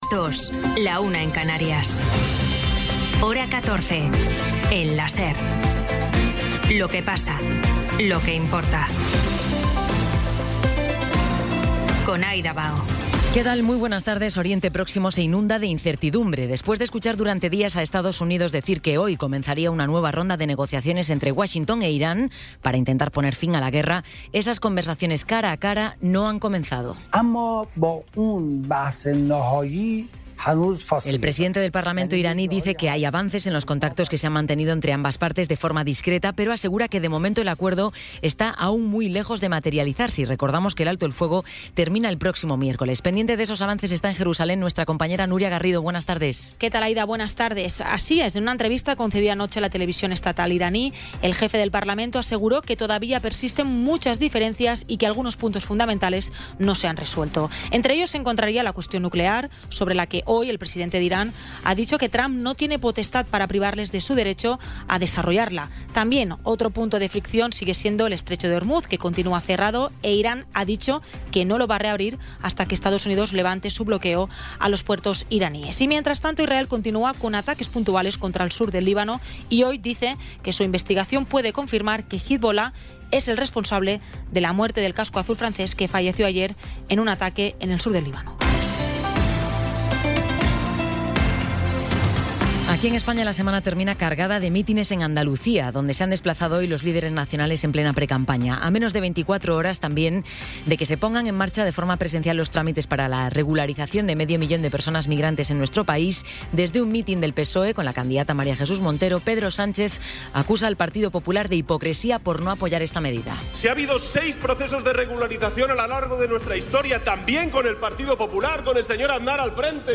Resumen informativo con las noticias más destacadas del 19 de abril de 2026 a las dos de la tarde.